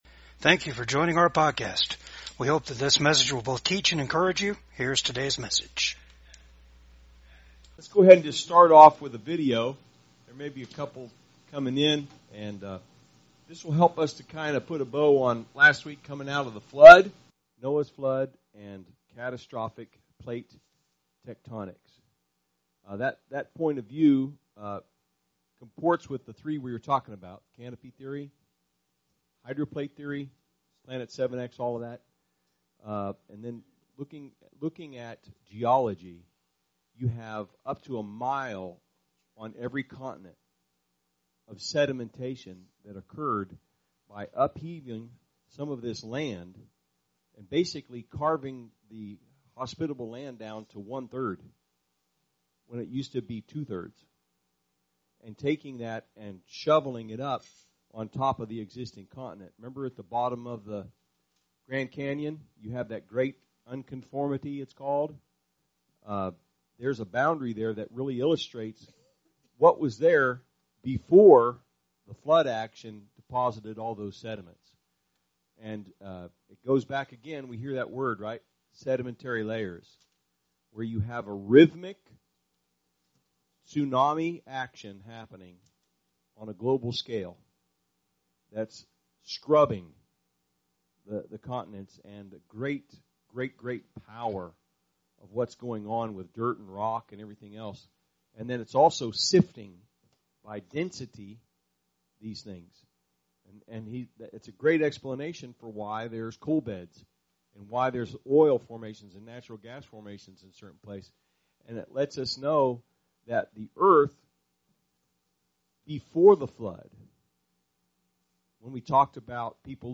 PORTIONS OF THIS PODCAST HAVE BEEN REDACTED DUE TO COPYRIGHT CONSTRAINTS.